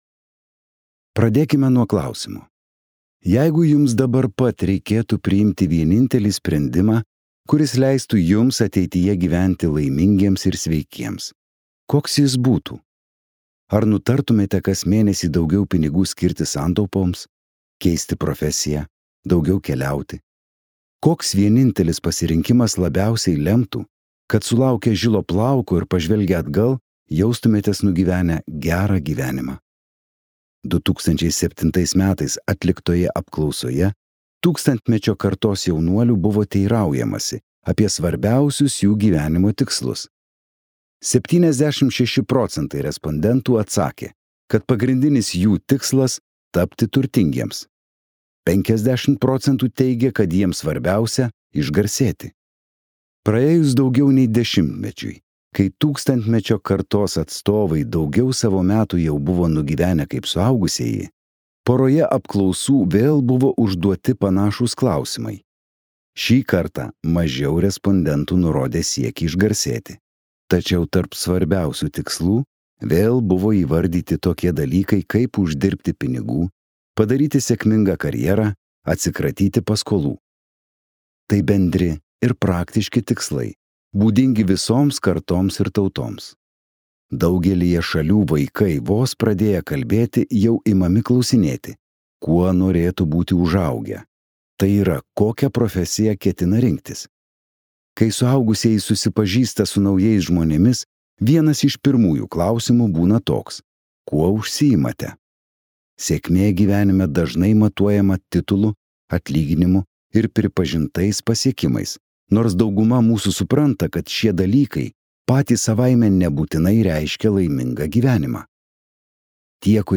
Ko mus išmokė ilgiausias pasaulyje laimės tyrimas | Audioknygos | baltos lankos